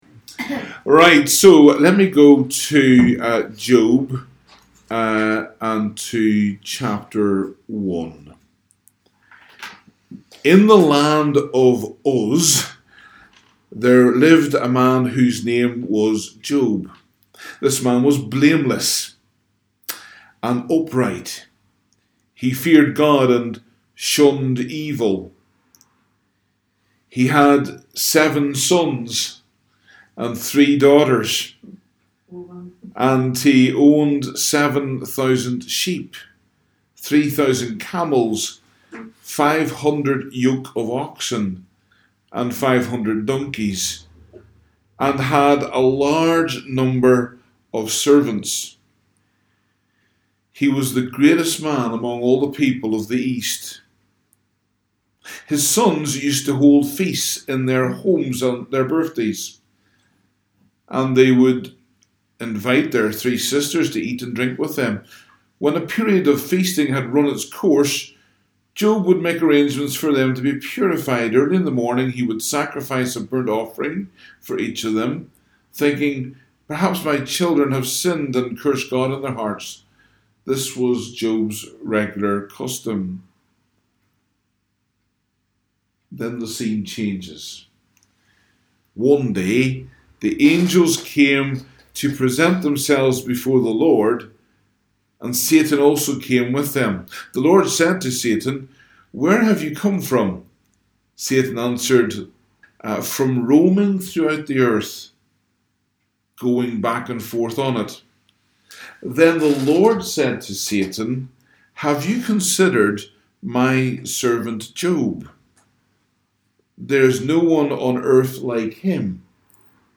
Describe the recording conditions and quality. Download the live Session as an MP3 audio file